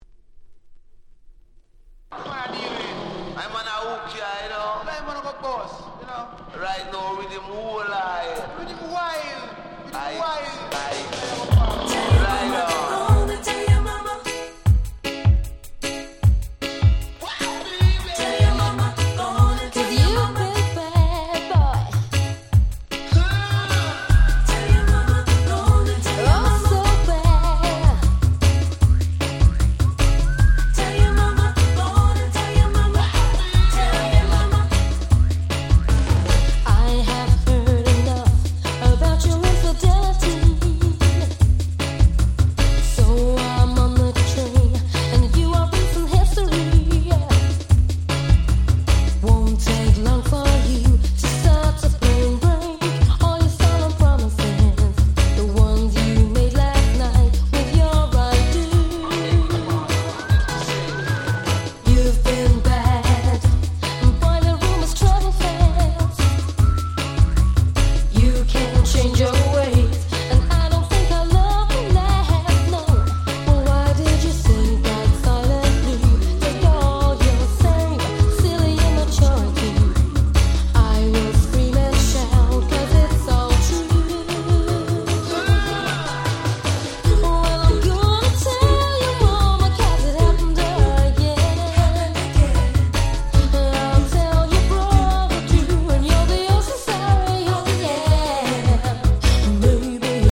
96' Super Nice Cover Reggae !!
カバーレゲエ ラバーズレゲエ